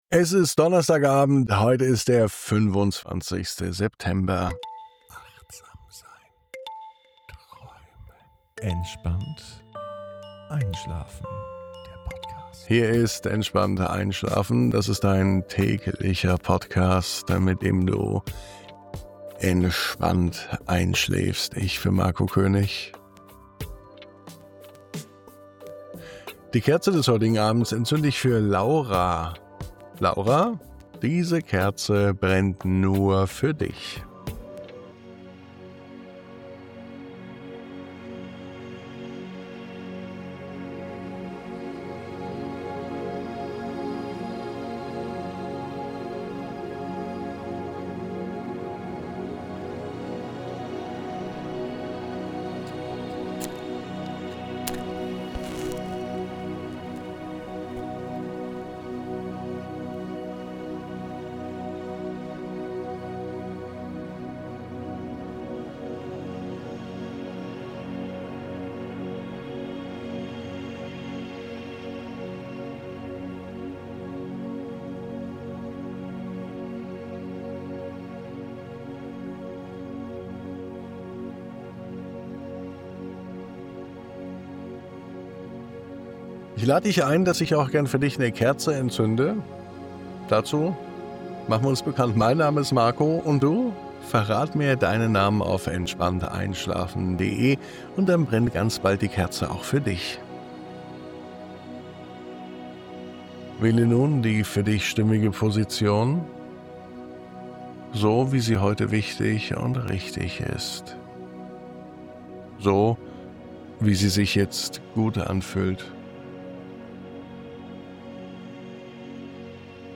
Diese Traumreise führt dich an einen weiten, menschenleeren Strand, wo das Meer in ruhigem Rhythmus atmet und der Sand sanft deine Schritte aufnimmt. Begleitet vom leisen Rauschen der Wellen darfst du dich ganz dem Moment hingeben… und dem Gefühl, nichts halten zu müssen.